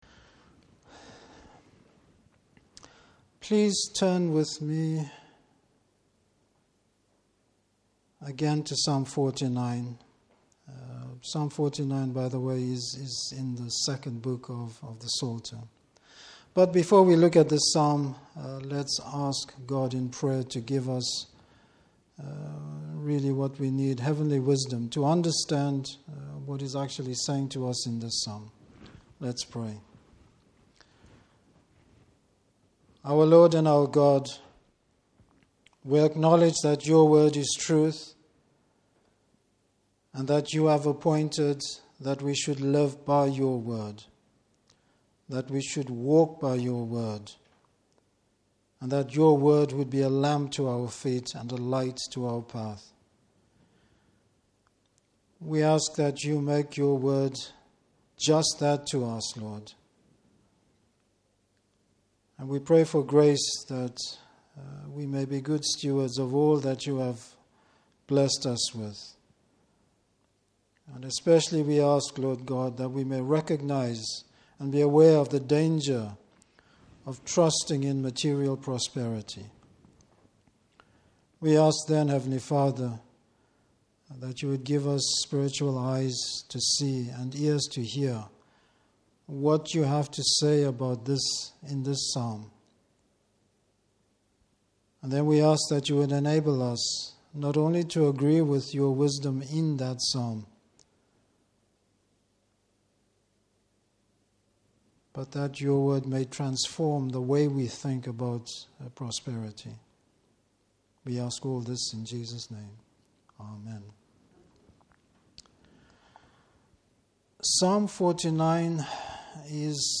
Service Type: Evening Service Spiritual wealth.